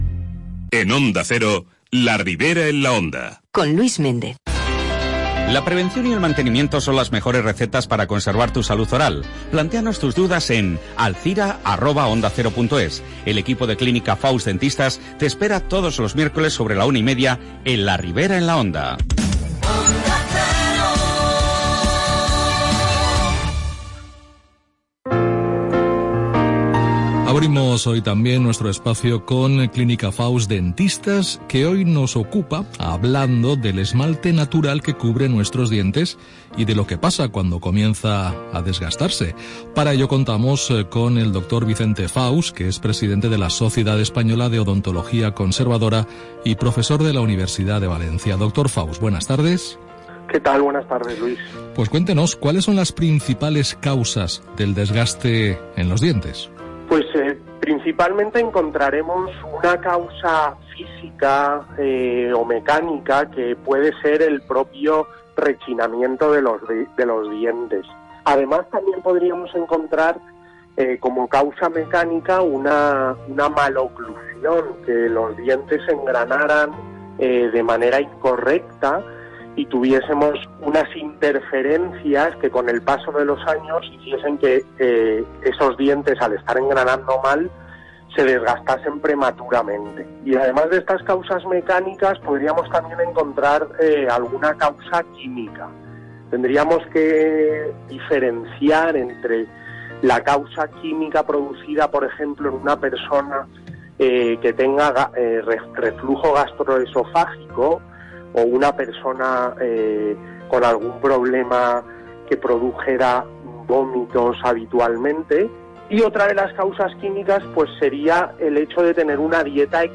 Entrevistas Onda Cero Alzira